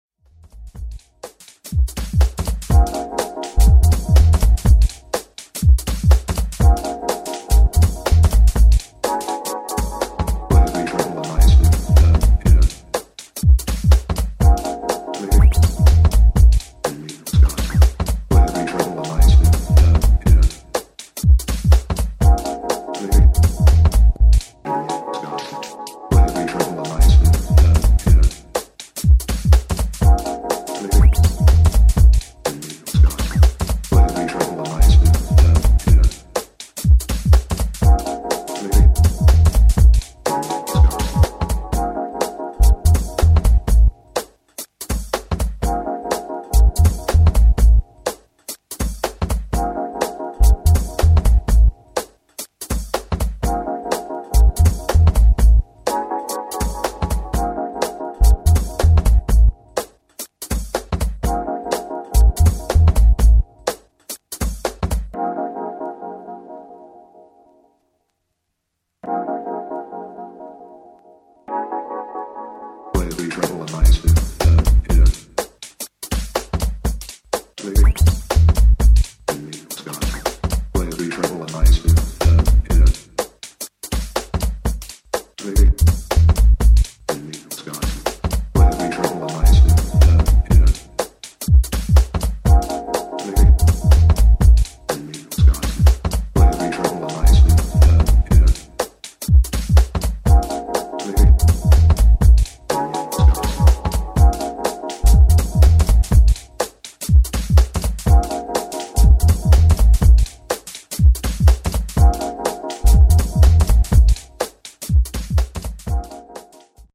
timeless deep house